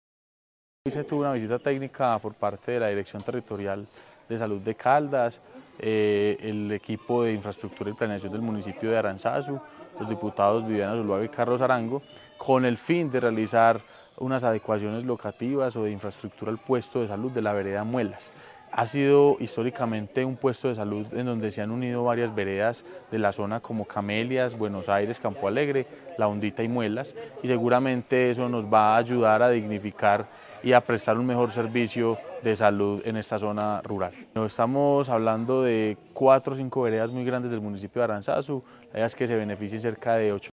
Sebastián Merchán Zuluaga, alcalde de Aranzazu